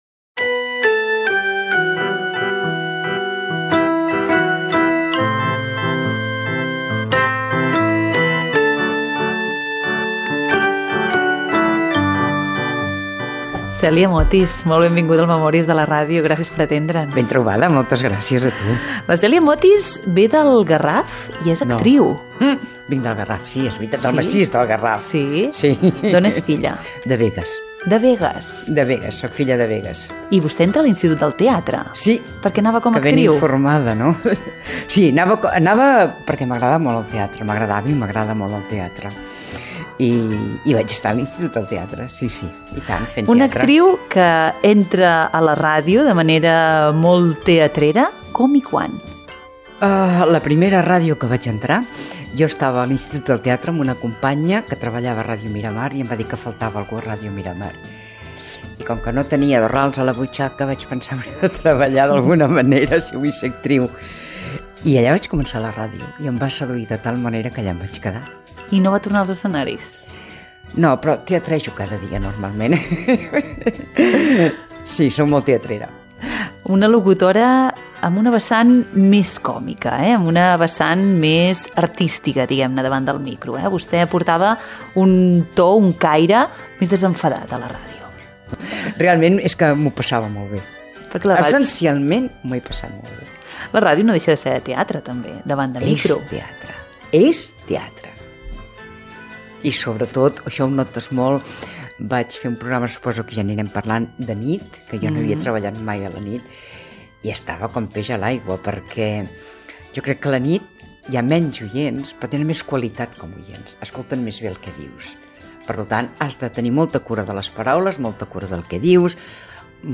Sintonia del programa, entrevista
Divulgació